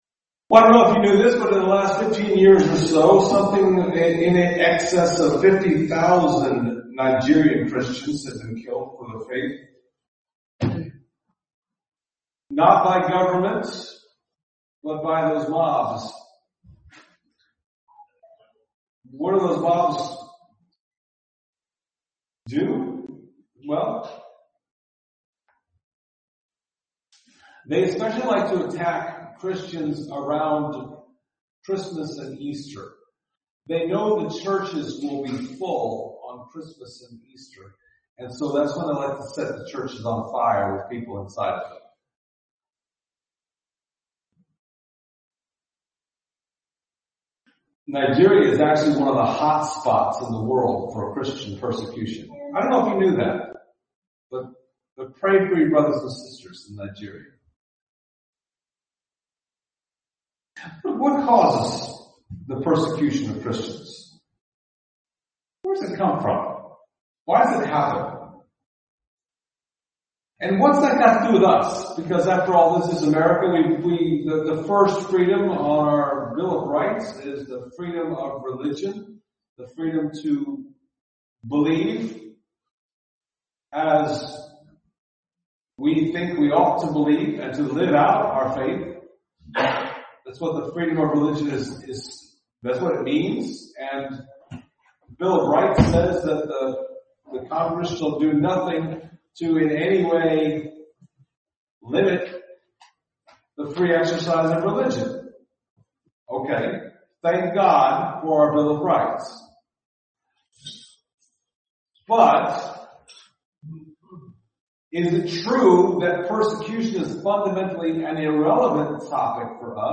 Passage: Acts 6:1-7:1 Service Type: Sunday Morning